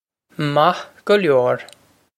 Pronunciation for how to say
Mah guh lyore
This is an approximate phonetic pronunciation of the phrase.